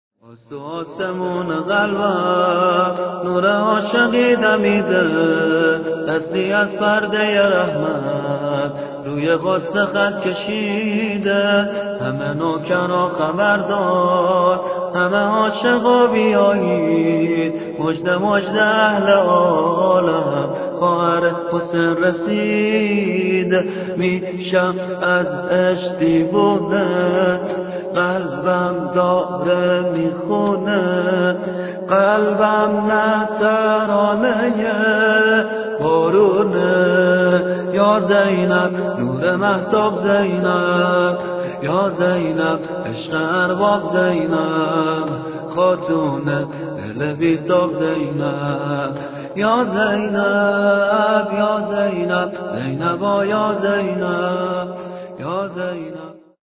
زینب کبری ولادت شور
شور